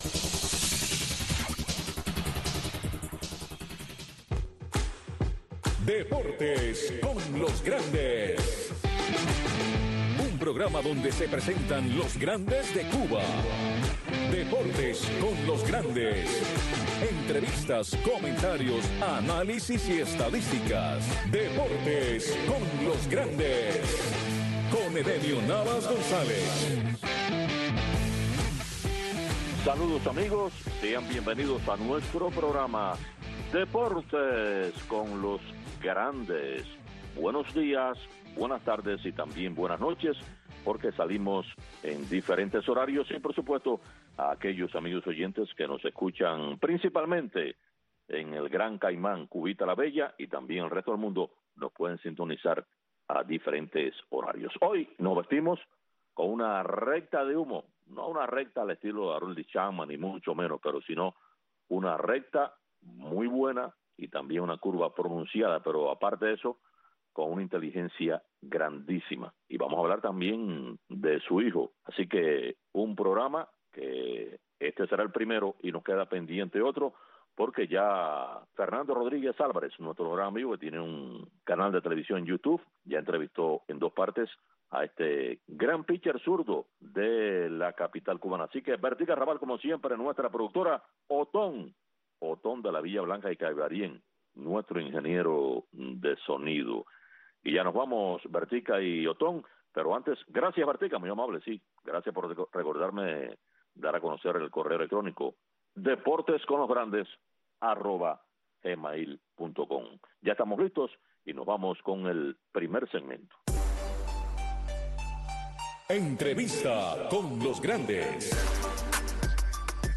Deportes con los Grandes. Un programa de Radio Marti, especializado en entrevistas, comentarios, análisis de los Grandes del deporte.